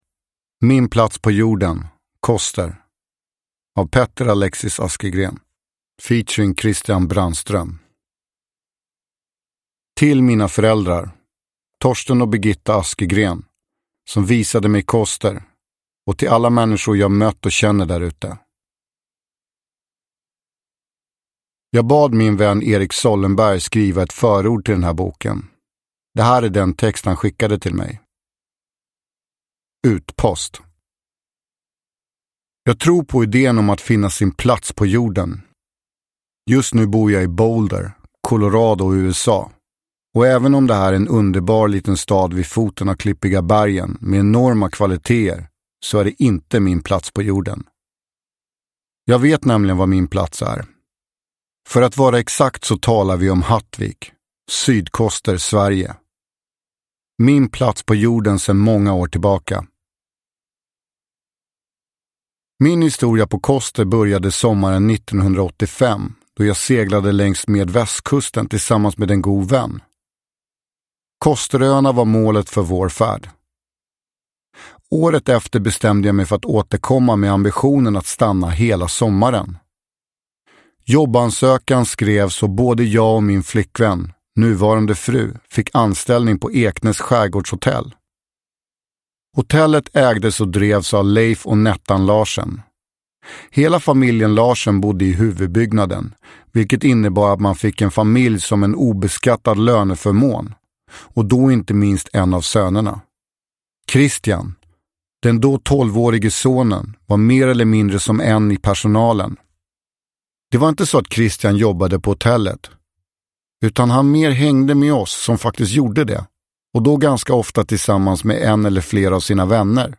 Min plats på jorden : Koster – Ljudbok – Laddas ner
Uppläsare: Petter Alexis Askergren